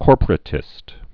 (kôrpər-ə-tĭst, kôrprə-tĭst)